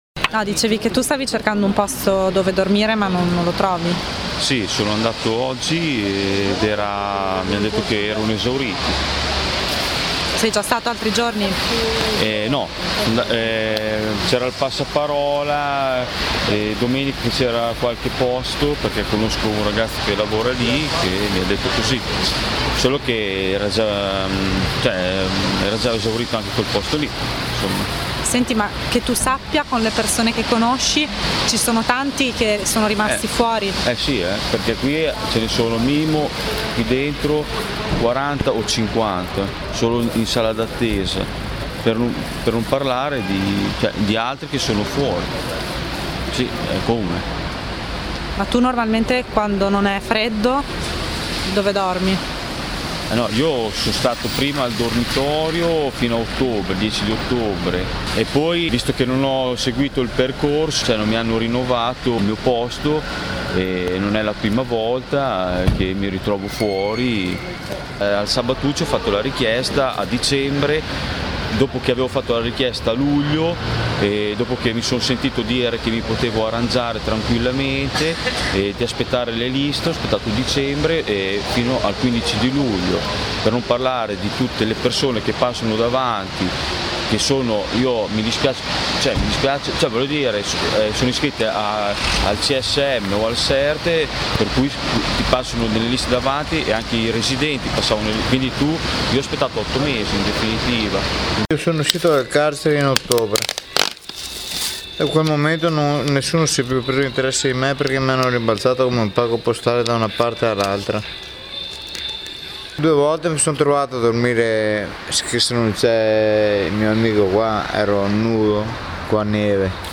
Reportage: vivere in strada
C’è poi chi dal dormitorio è stato mandato via e si trova a dormire fuori, magari alla stazione.